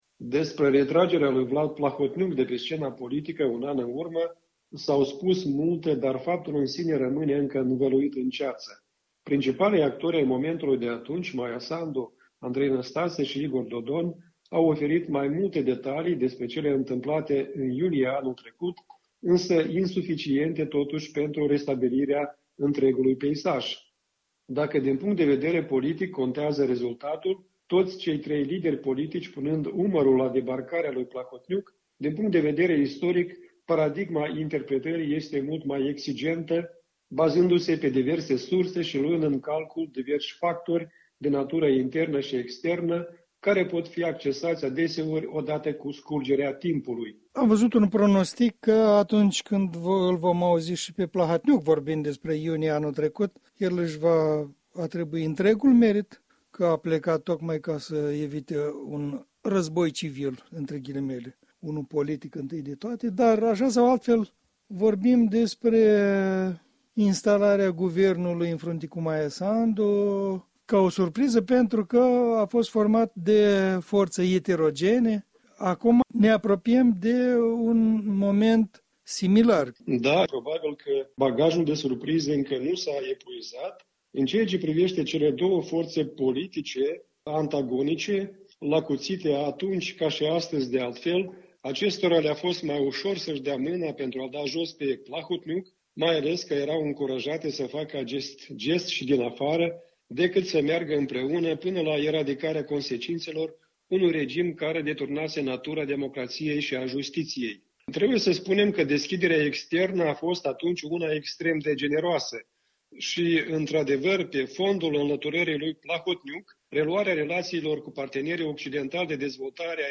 Un punct de vedere săptămânal în dialog.